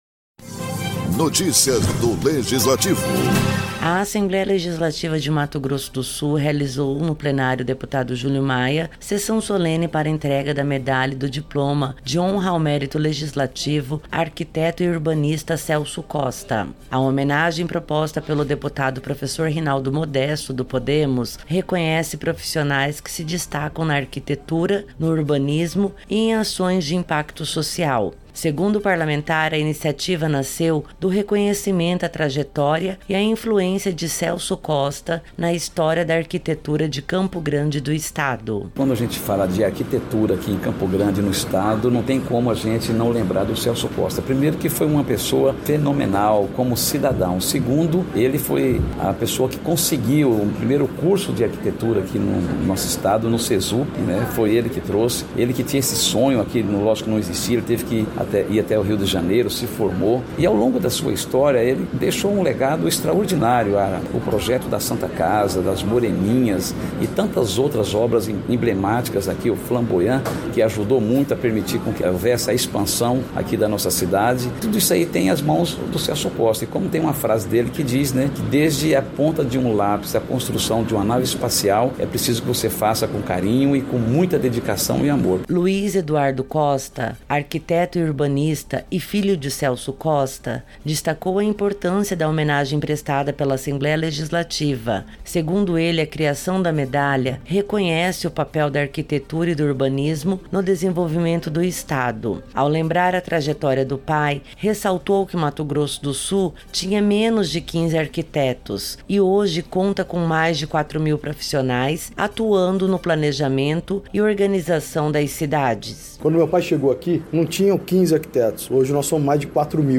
A Assembleia Legislativa de Mato Grosso do Sul promoveu sessão solene no Plenário Deputado Júlio Maia para a entrega da Medalha e do Diploma de Honra ao Mérito Legislativo Arquiteto e Urbanista Celso Costa. A iniciativa, proposta pelo deputado Professor Rinaldo Modesto, reconhece profissionais que se destacam na arquitetura, no urbanismo e em ações de impacto social.